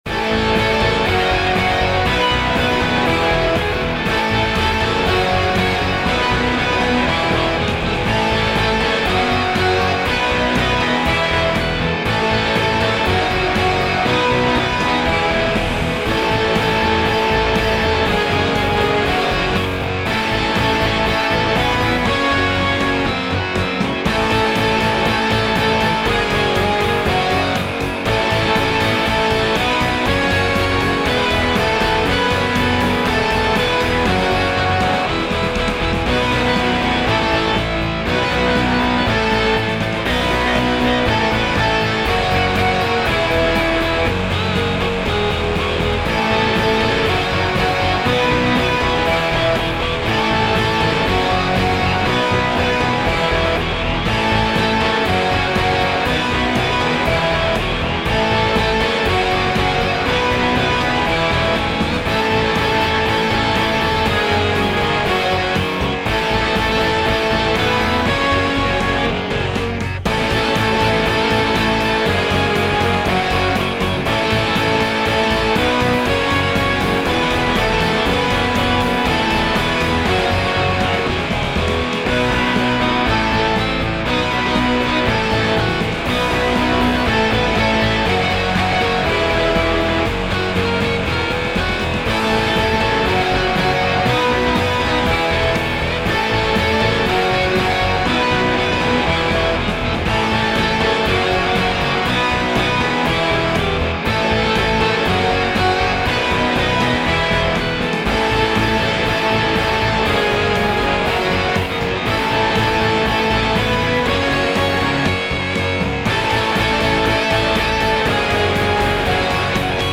midi-demo 3